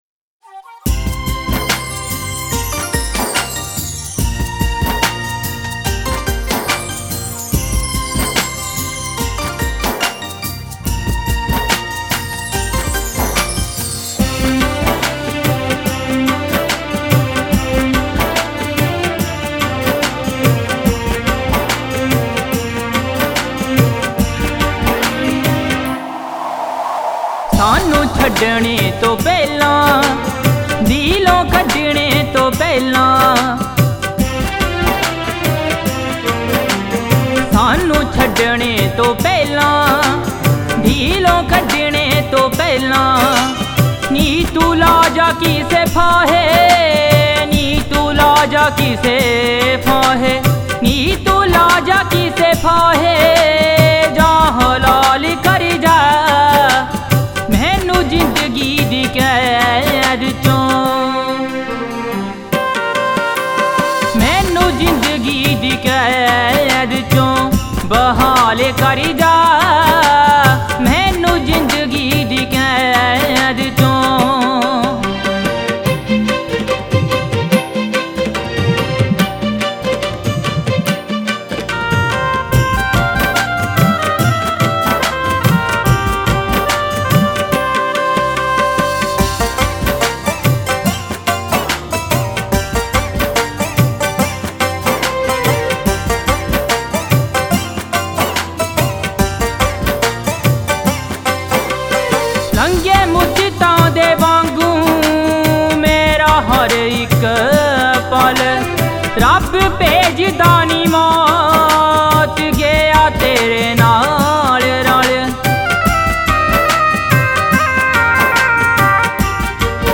Punjabi Bhangra MP3 Songs